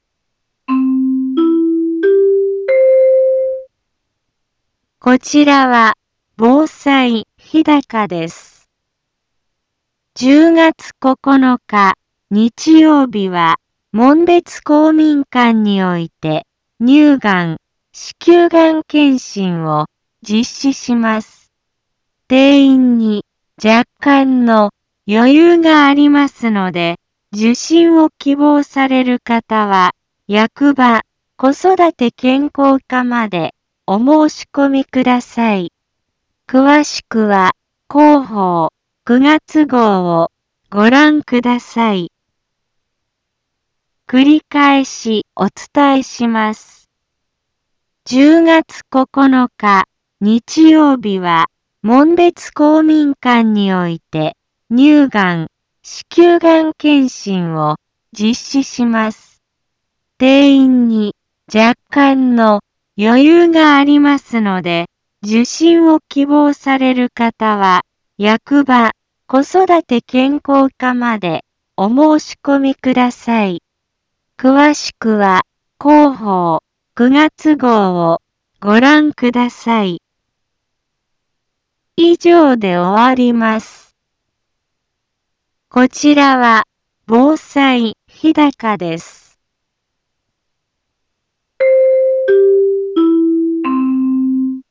Back Home 一般放送情報 音声放送 再生 一般放送情報 登録日時：2022-09-12 10:03:20 タイトル：乳がん・子宮頸がん検診のお知らせ インフォメーション：こちらは防災日高です。